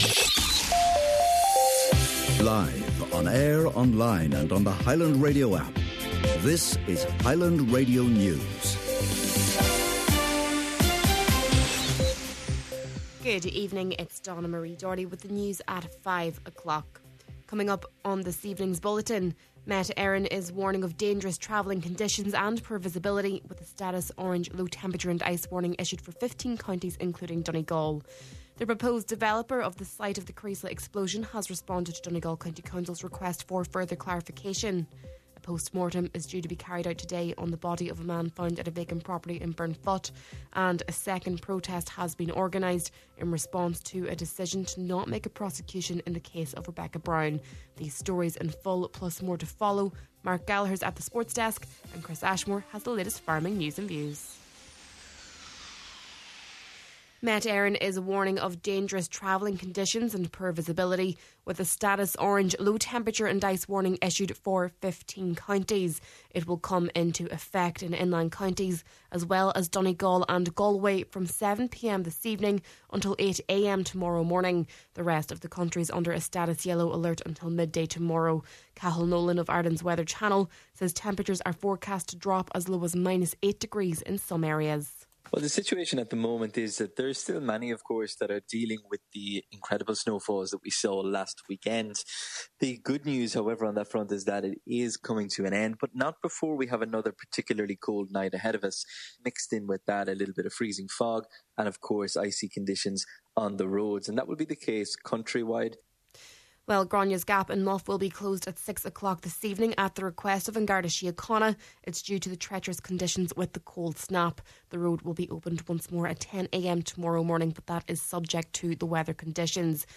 Main Evening News, Sport, Farming News and Obituaries – Thursday, January 9th